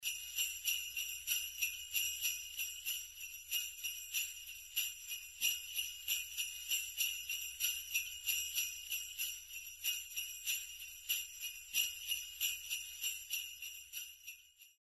Здесь вы можете слушать онлайн и скачать бесплатно чистые, серебристые перезвоны, которые ассоциируются с Рождеством, зимней сказкой и санями Деда Мороза.
Красивый звук новогодних бубенцов